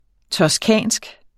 Udtale [ tʌsˈkæˀnsg ]